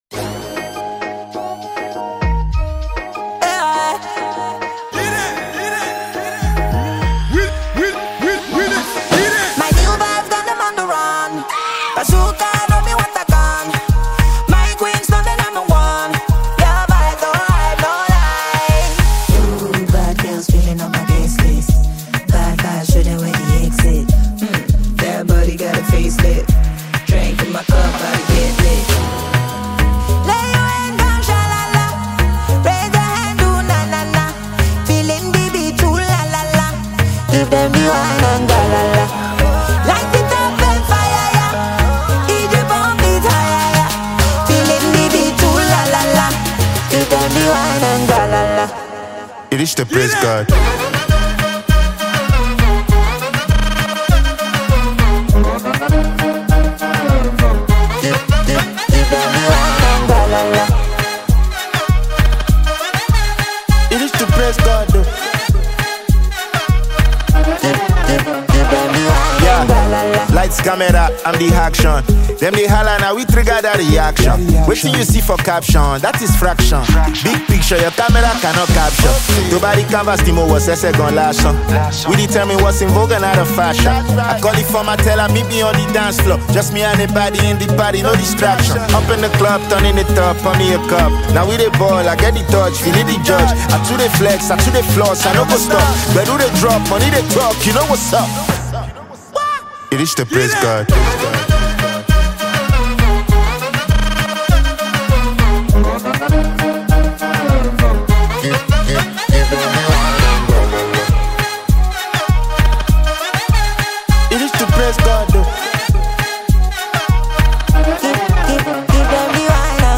rapper
Pop-genre song